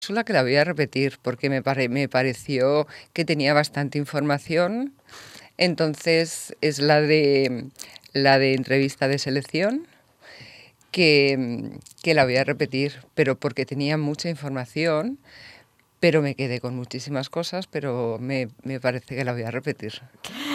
Aquesta setmana hem anat a Ràdio Mollet a explicar les càpsules que oferim als ciutadans i volem compartir amb vosaltres diferents talls de veu que demostren que estem assolint els objectius amb els usuaris que hi assisteixen: